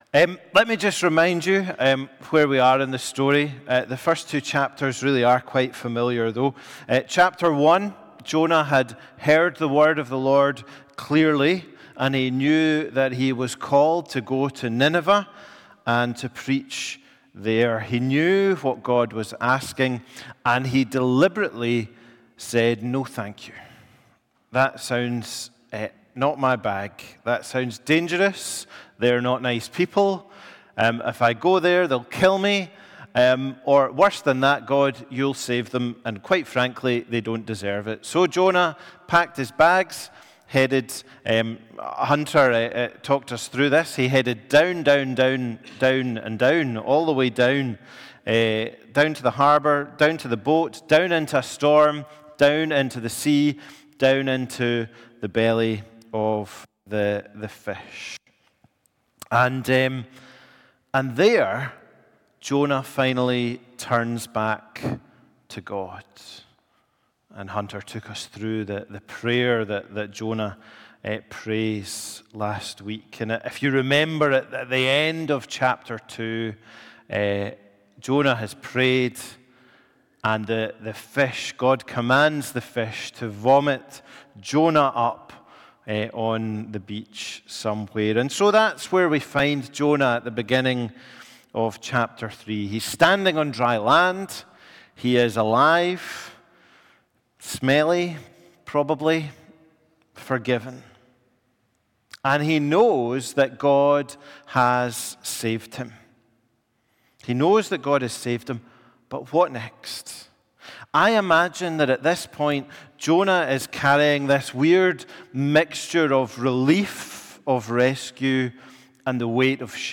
A Sermon from the series "Jonah."